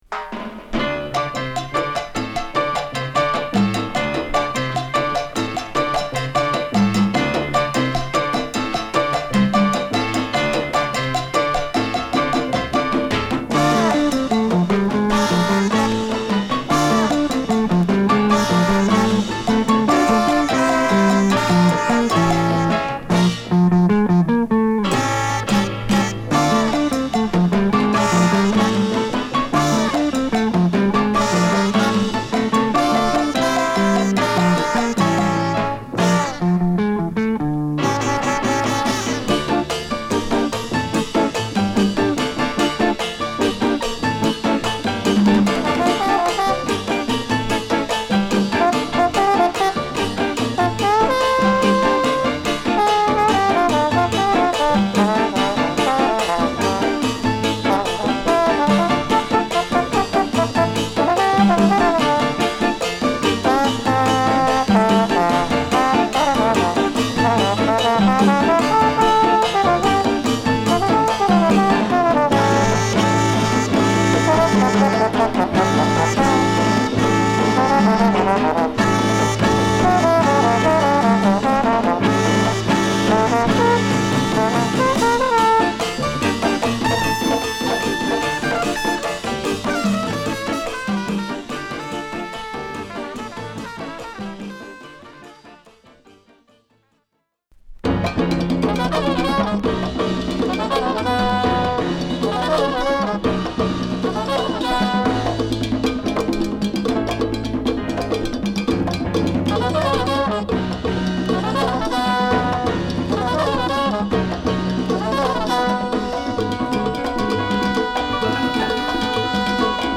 素晴らしいラテン・ジャズを収録！